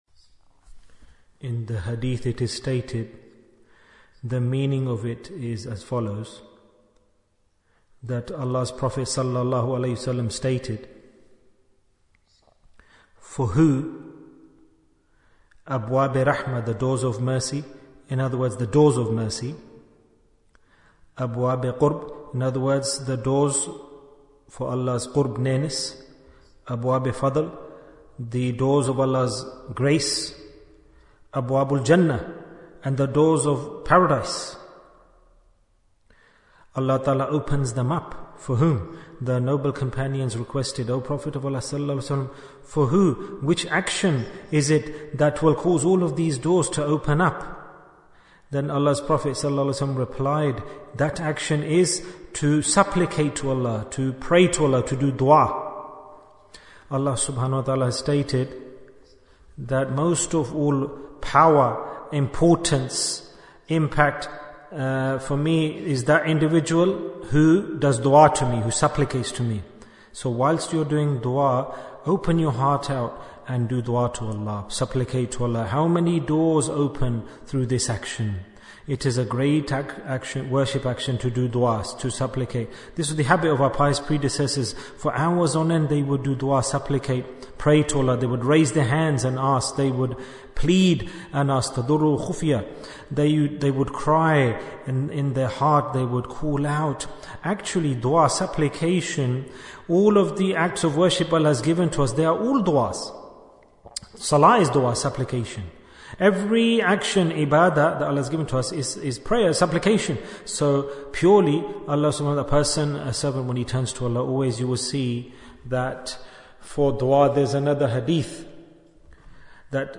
Talk before Dhikr 78 minutes24th September, 2024